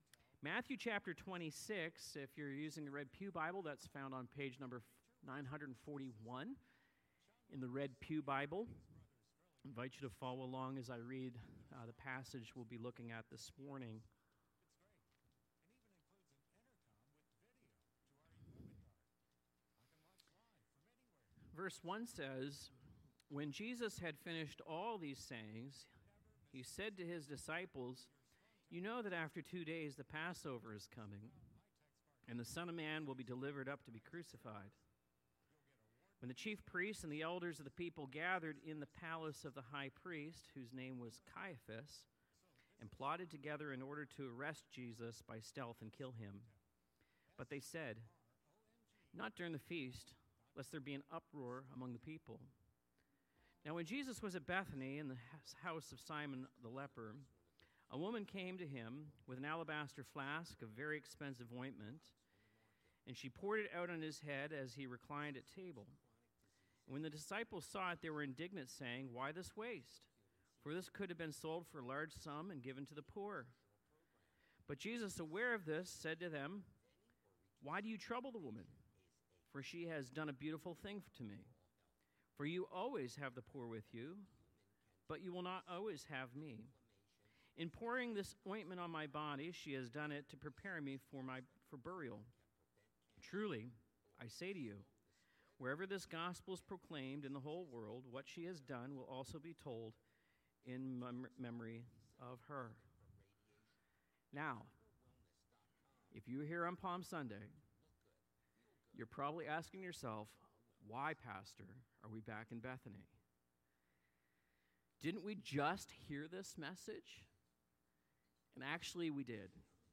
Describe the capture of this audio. Sermons, Seminars, and Scripture Exposition from the Pulpit of Tabernacle Bible Church, Honesdale, PA